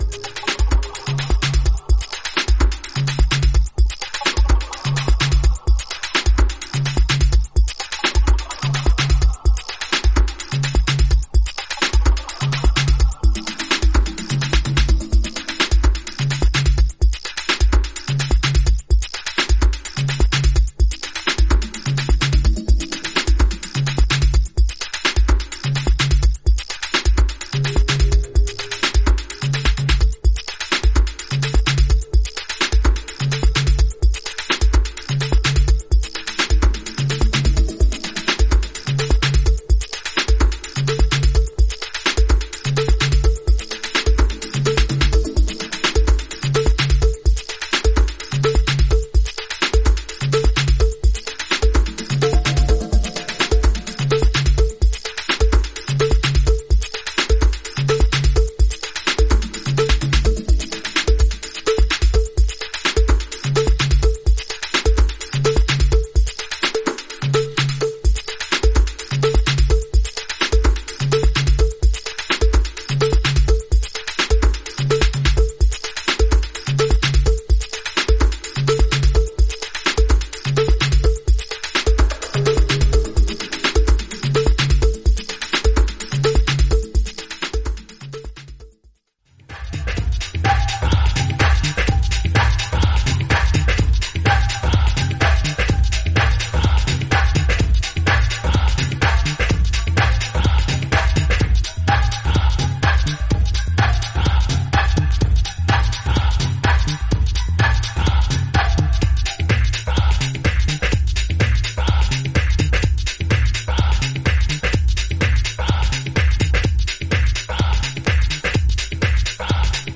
デトロイトテクノやアーリーシカゴを通過した、UKエレクトリック・ハウス(テックハウス）名作！